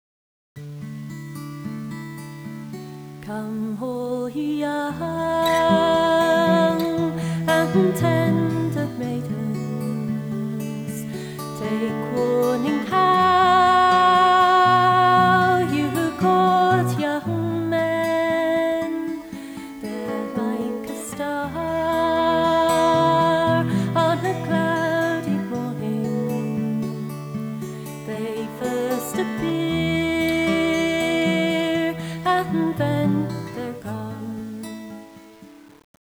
Irish Music
guitar
guitar.wav